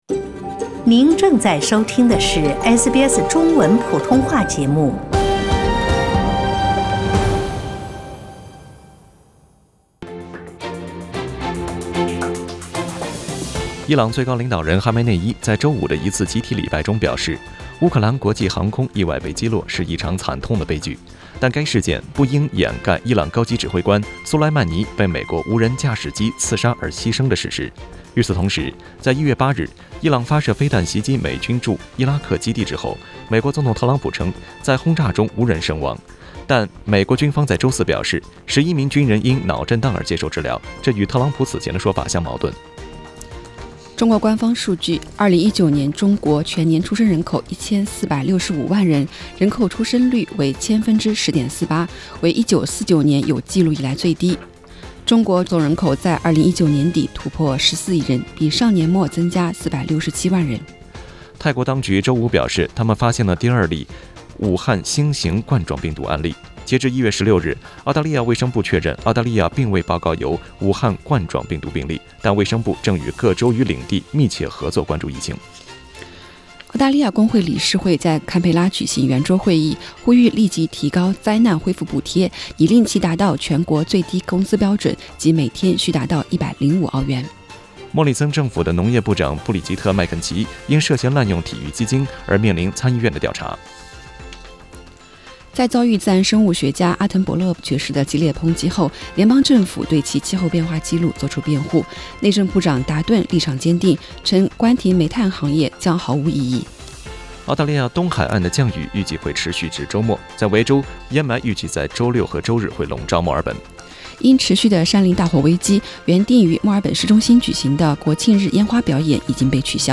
SBS Chinese Morning News Source: Shutterstock